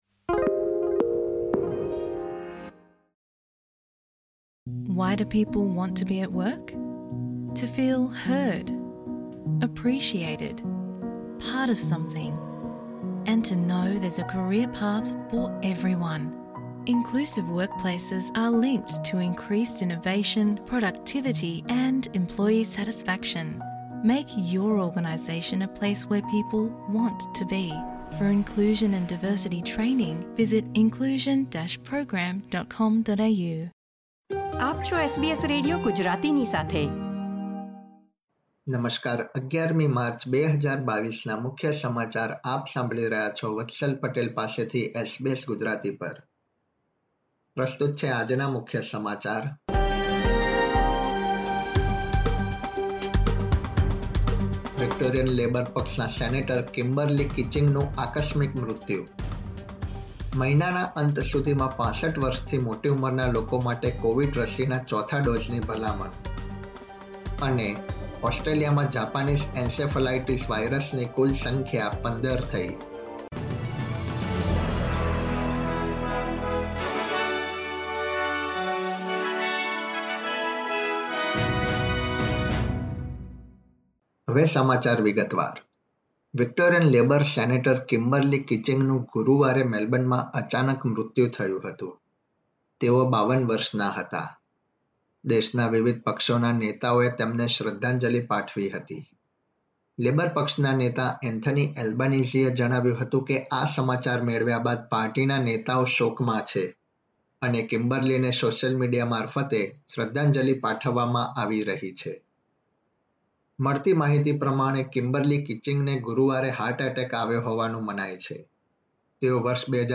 SBS Gujarati News Bulletin 11 March 2022